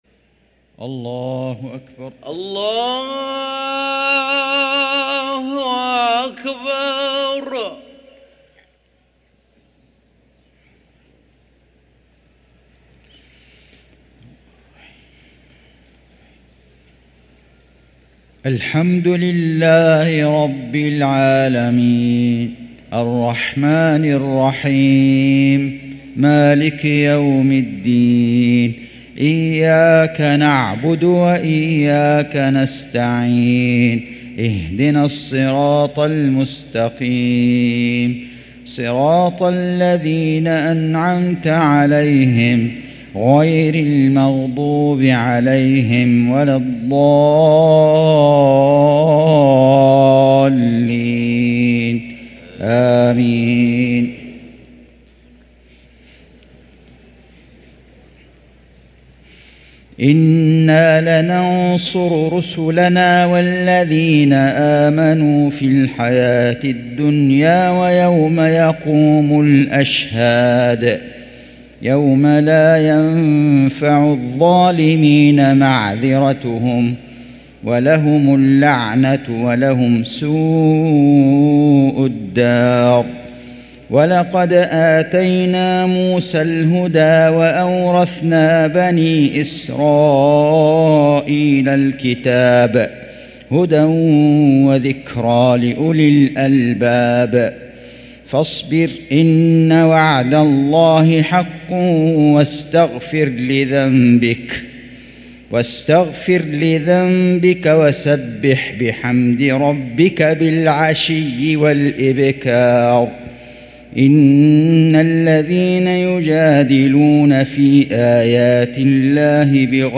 صلاة المغرب للقارئ فيصل غزاوي 6 رجب 1445 هـ
تِلَاوَات الْحَرَمَيْن .